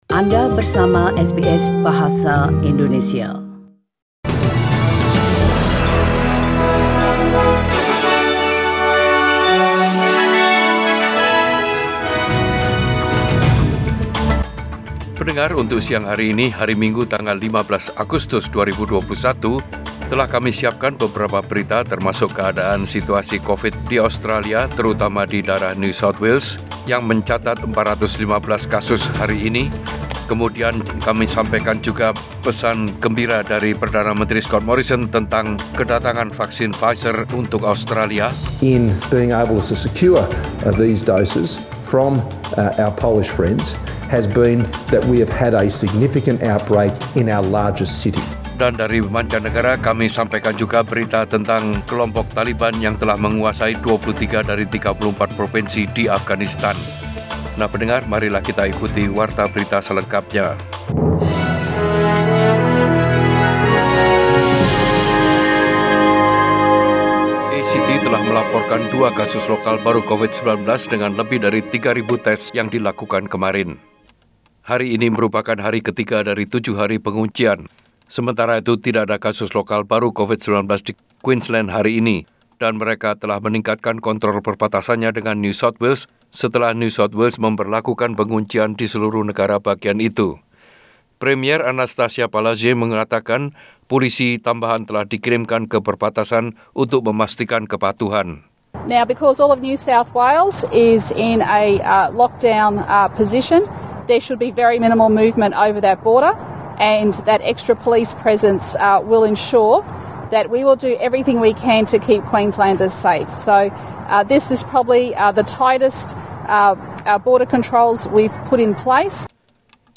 SBS Radio News in Bahasa Indonesia - 15 August 2021
Warta Berita Radio SBS dalam Bahasa Indonesia Source: SBS